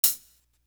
Feel Me Hat.wav